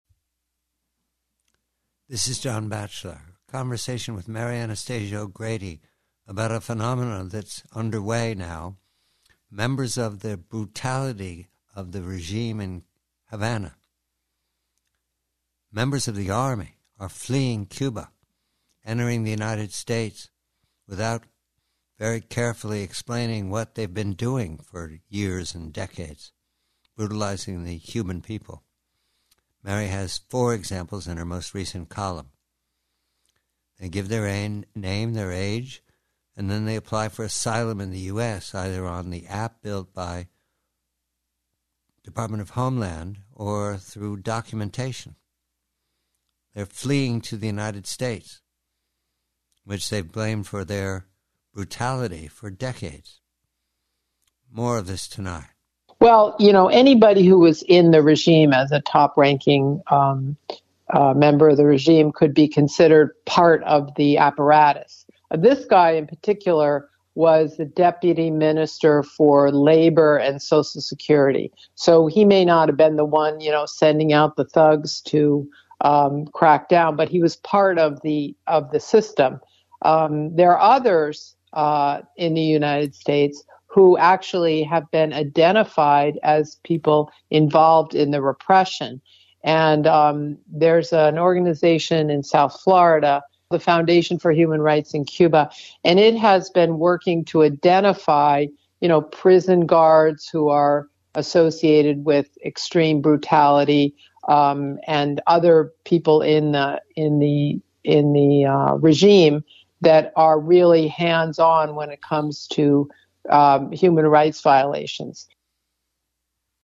Preview: Conversation with colleague Mary Anastasia O'Grady regarding the fact that members of the brutal Cuban regime are fleeing their uniforms and jobs to enter the US as sanctuary seekers.